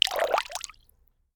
water-splash-05-2
Category 🌿 Nature
bath bathroom bubble burp click drain dribble dripping sound effect free sound royalty free Nature